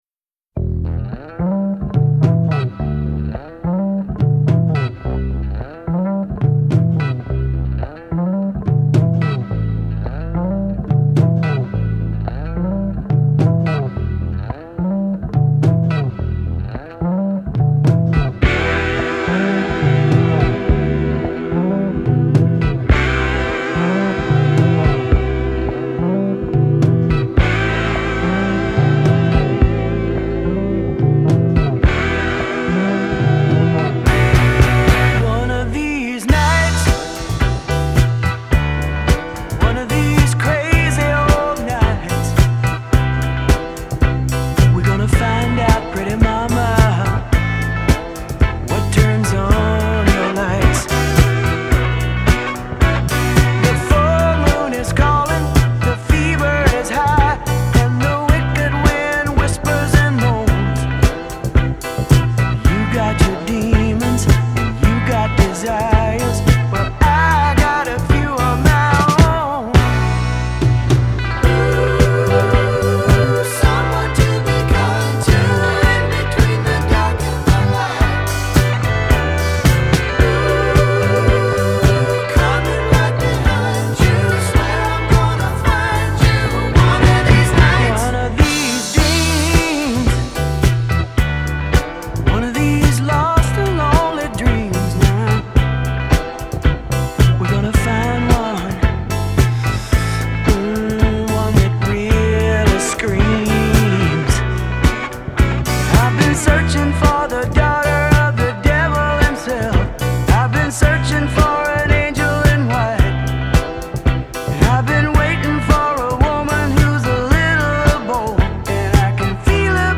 That falsetto chorus still gets me every time.